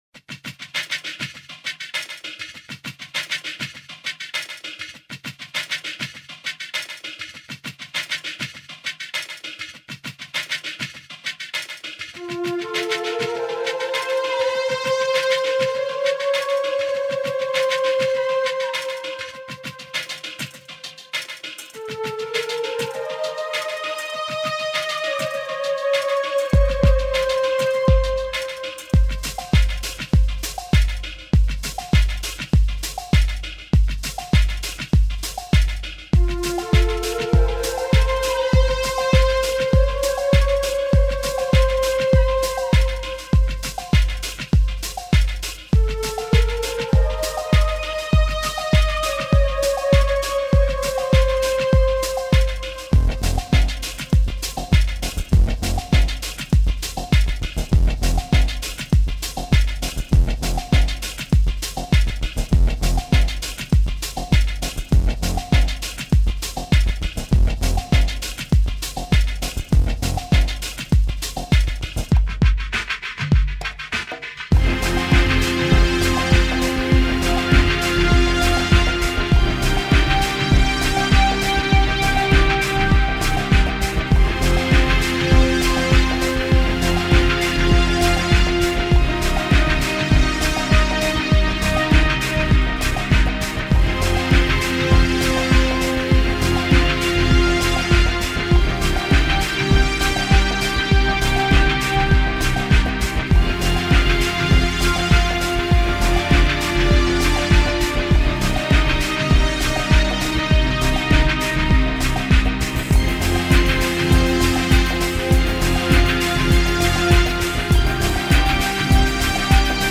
تلفیقی از موسیقی ارکسترال و الکترونیکی